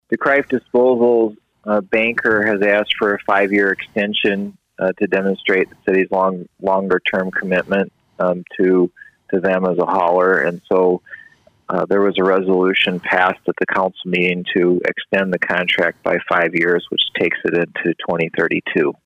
During Wednesday’s City Council meeting, the Council took up a De Kruif Disposal request to extend their current garbage hauling contract with the City for an additional 5-year term. City Manager Sam Kooiker explains.